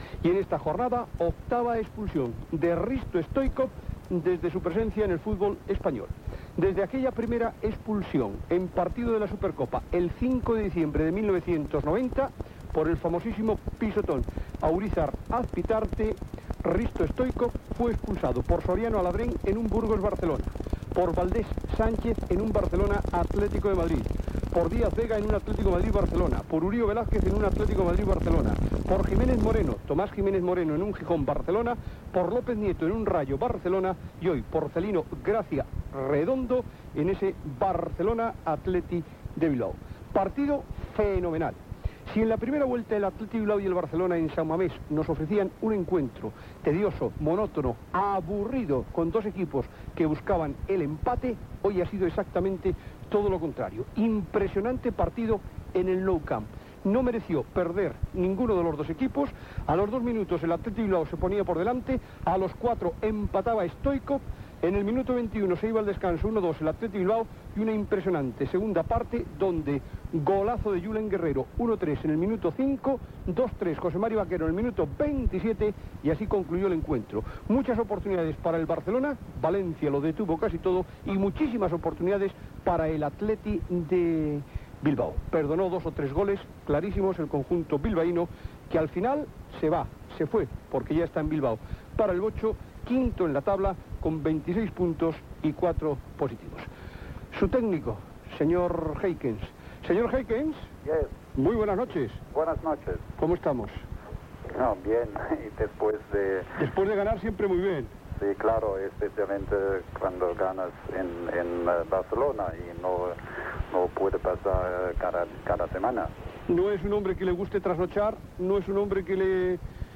Gènere radiofònic Esportiu